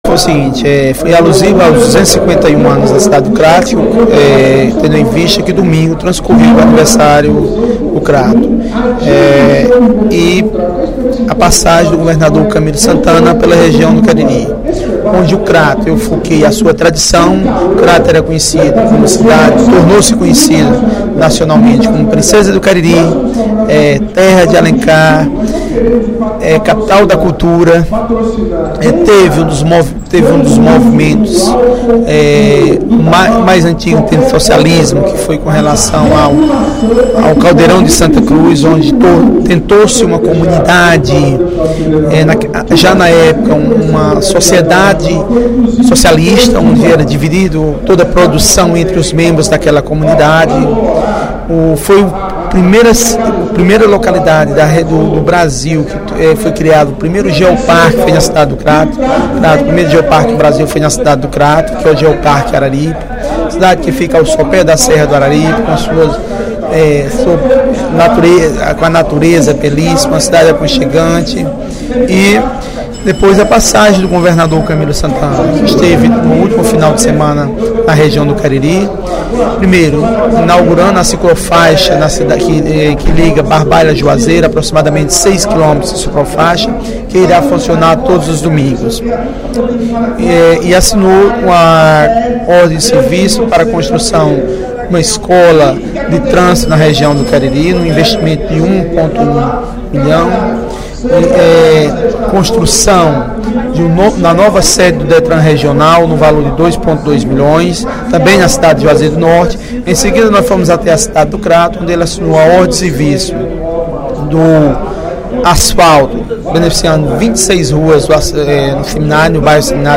“Nossa querida cidade natal foi elevada à condição de vila em 1764, sendo sua história motivo de orgulho para os cratenses”, disse o parlamentar, durante pronunciamento no primeiro expediente da sessão plenária desta terça-feira (23/06).
Em aparte, os deputados Bruno Gonçalves (PEN), Walter Cavalcante (PMDB) e Ely Aguiar (PSDC) elogiaram o pronunciamento.